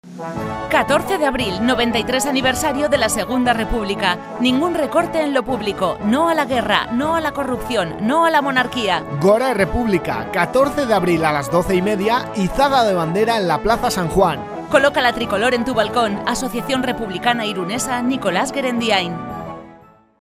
Iragarki laburrak "Radio Irun" irratian, apirilaren 9tik 14ra